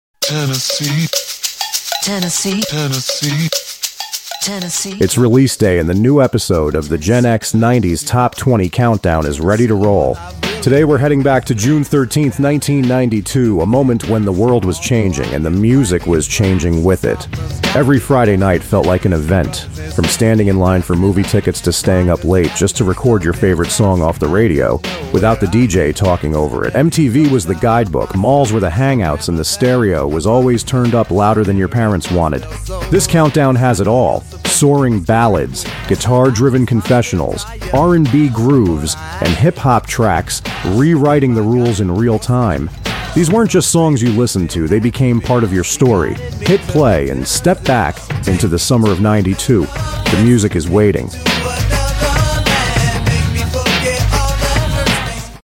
Pop, rock, R&B, and hip-hop collided in one unforgettable summer mix.